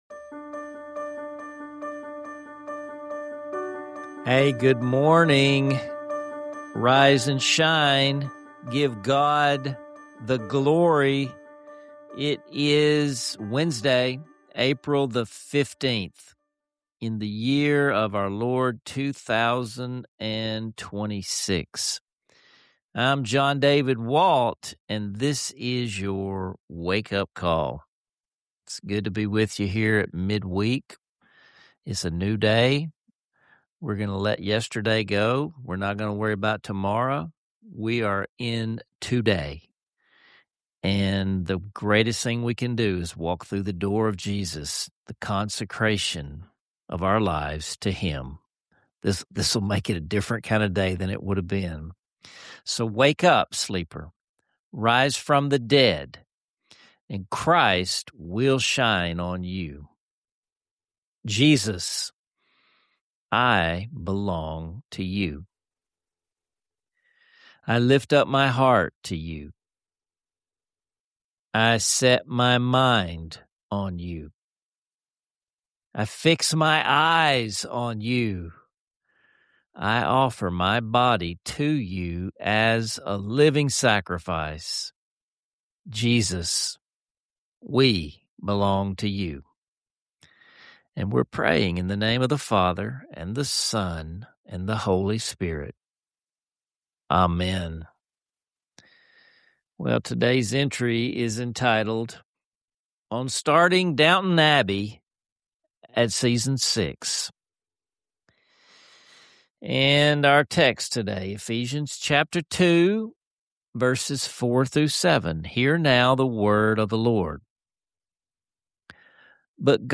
CHAPTERS: 00:00 Morning Prayer and Consecration to Jesus